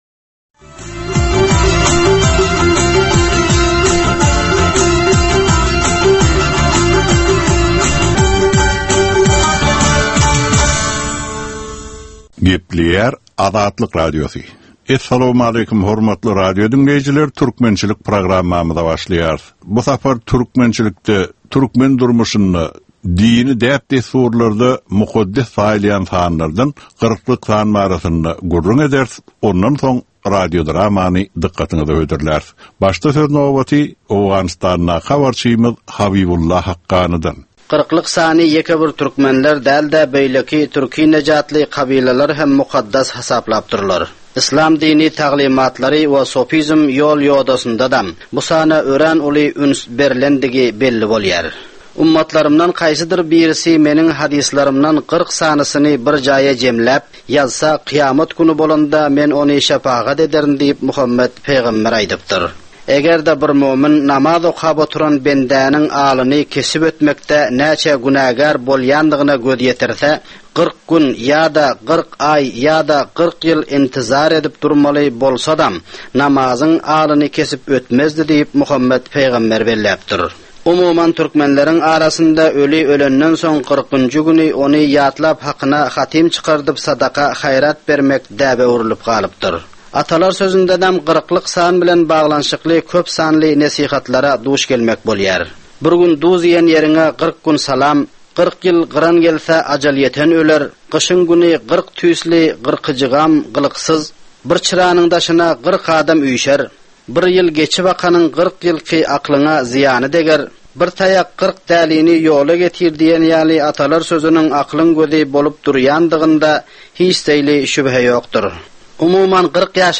Türkmen halkynyň däp-dessurlary we olaryň dürli meseleleri barada ýörite gepleşik. Bu programmanyň dowamynda türkmen jemgyýetiniň şu günki meseleleri barada taýýarlanylan radio-dramalar hem efire berilýär.